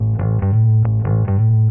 描述：电贝司
Tag: 贝司